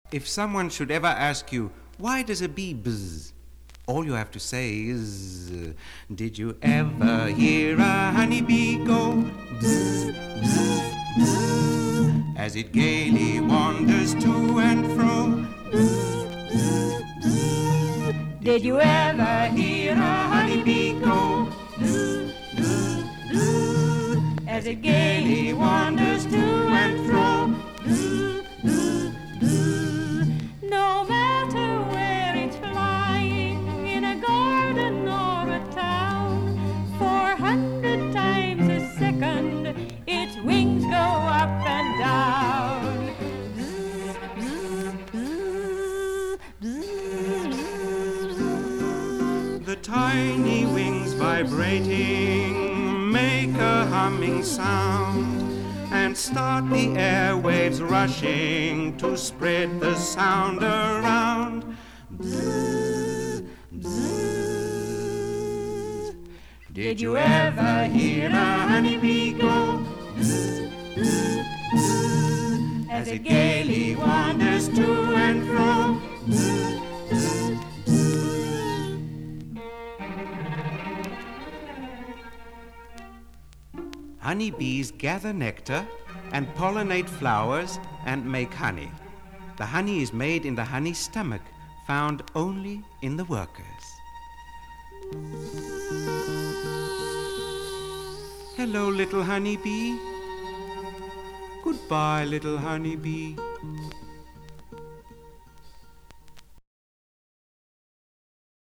bee_bzzz-160.mp3